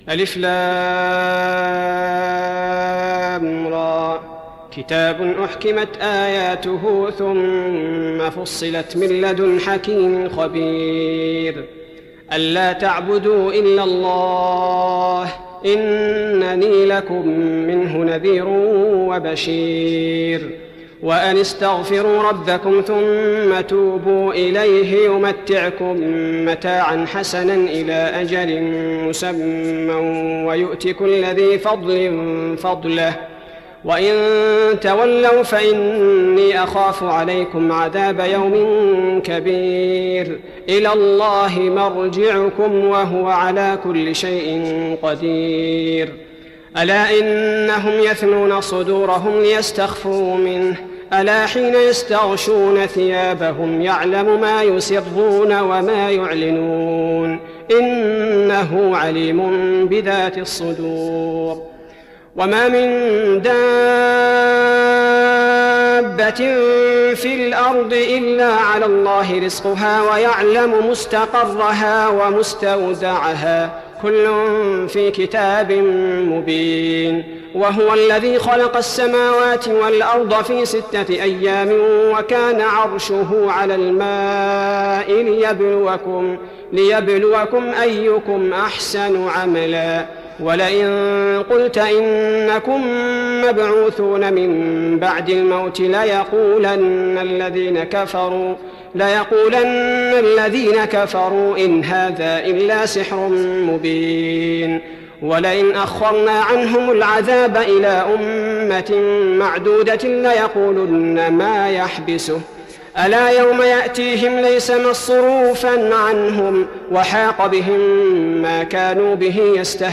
تراويح رمضان 1415هـ فواتح سورة هود (1-107) Taraweeh Ramadan 1415H from Surah Hud > تراويح الحرم النبوي عام 1415 🕌 > التراويح - تلاوات الحرمين